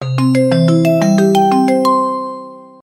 без слов
короткие